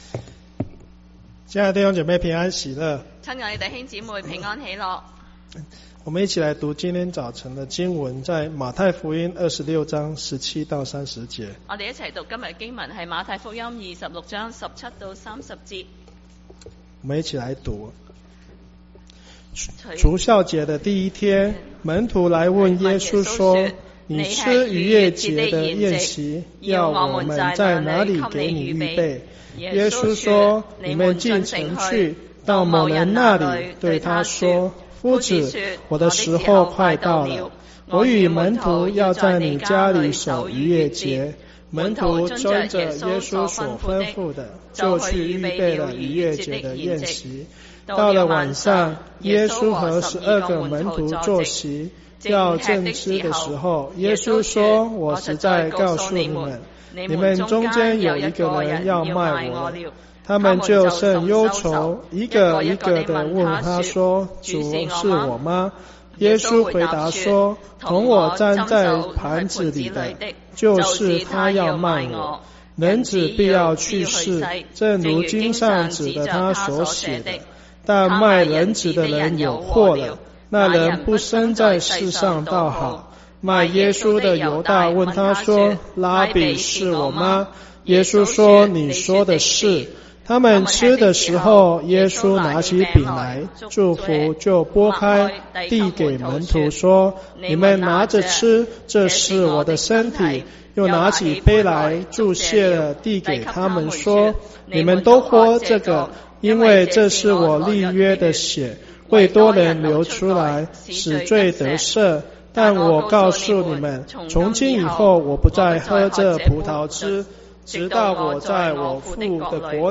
中文堂講道信息 | First Baptist Church of Flushing